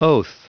Prononciation du mot oath en anglais (fichier audio)
Prononciation du mot : oath